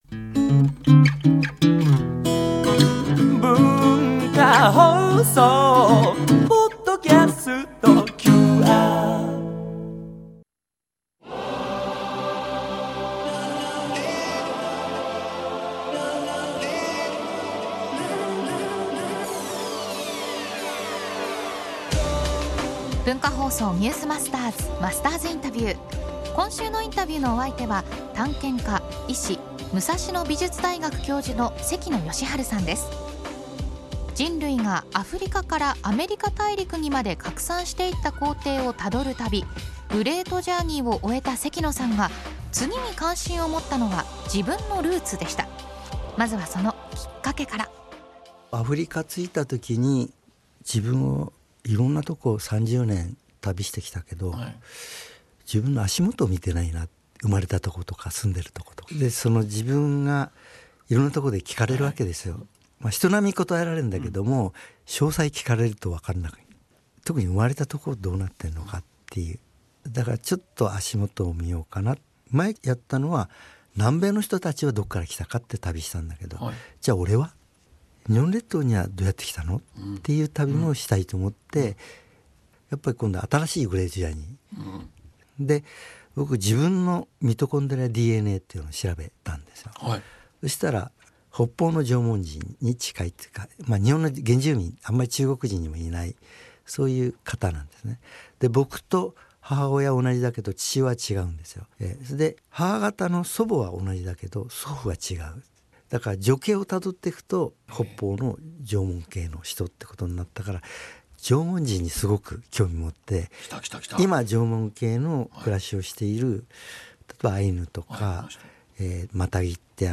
今週のインタビューのお相手は探検家・医師・武蔵野美術大学教授の関野吉晴さん。
（月）～（金）AM7：00～9：00　文化放送にて生放送！